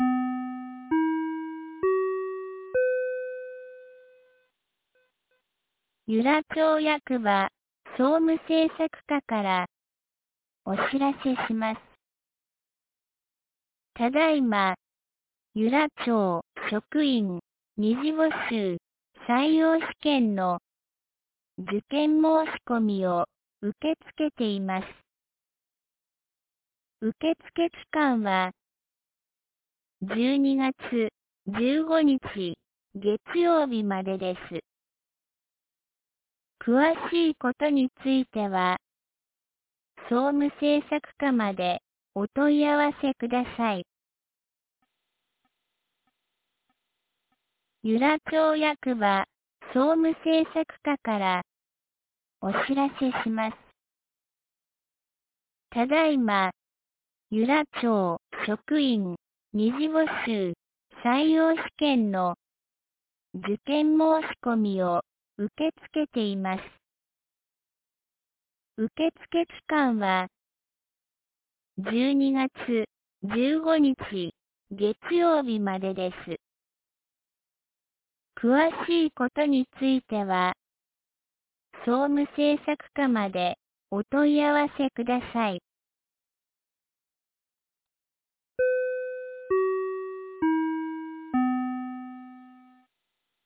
2025年11月16日 12時22分に、由良町から全地区へ放送がありました。